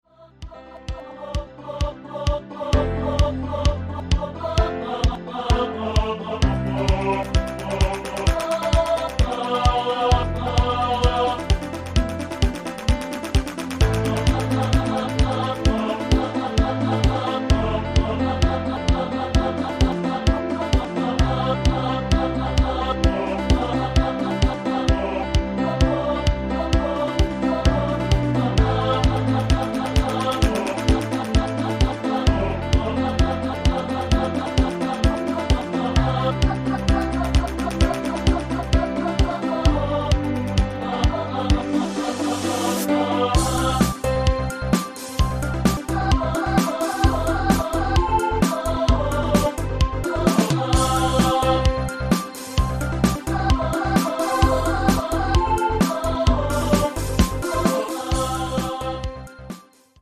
MIDI Multitrack
Canal 01: Synth Bass 1 – 583 notes
Canal 03: Bright Acoustic Piano – 412 notes
Canal 07: Electric Guitar (muted) – 271 notes
Canal 09: Choir Aahs – 449 notes
Canal 10: Drums – 913 notes
Canal 15: Rock Organ – 72 notes